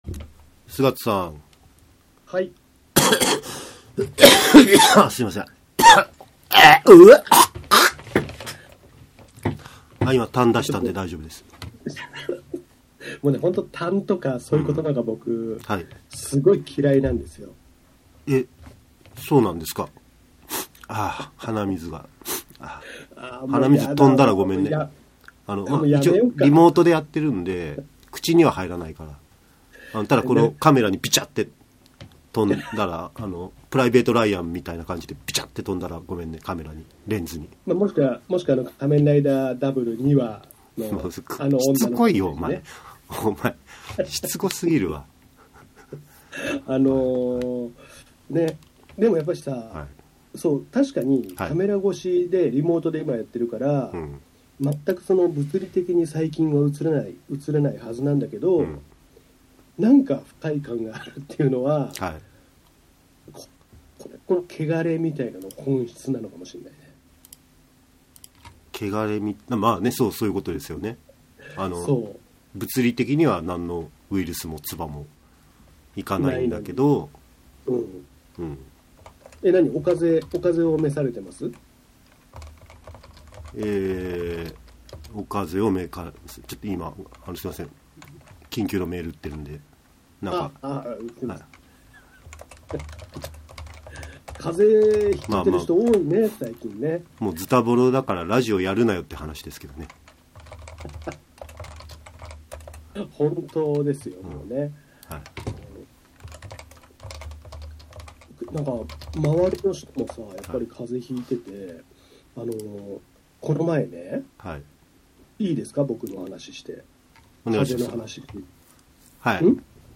風邪をおしてまで収録する執念をご堪能ください。